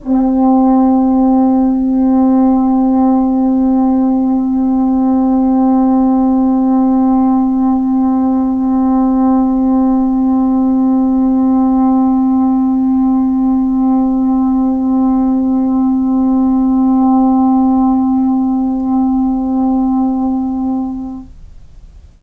trombone
C4.wav